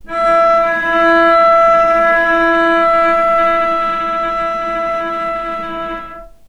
Strings / cello / sul-ponticello / vc_sp-E4-mf.AIF
vc_sp-E4-mf.AIF